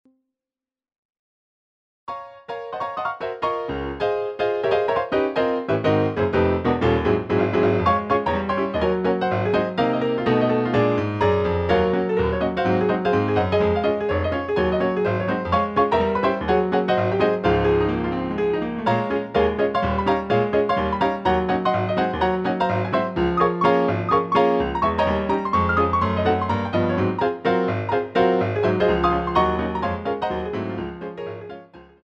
CD quality digital audio Mp3 file
using the stereo sampled sound of a Yamaha Grand Piano.